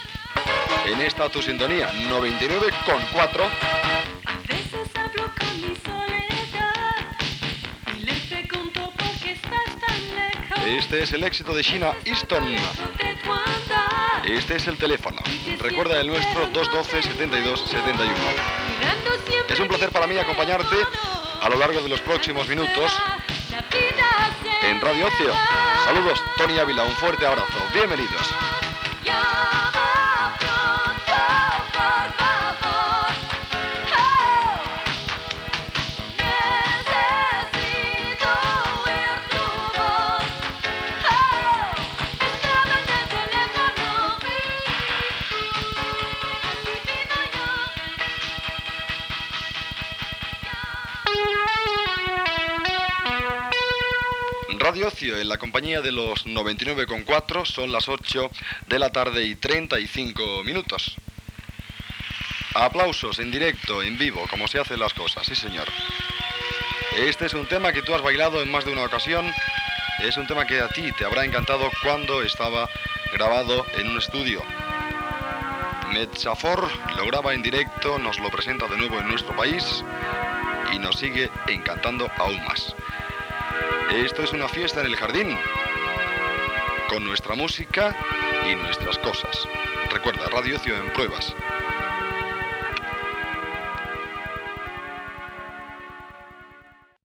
d7843ffceac545abab0bf9185f0f15ceda585425.mp3 Títol Radio Ocio Emissora Radio Ocio Titularitat Privada local Descripció Tema musical, telèfon, indicatiu i tema musical.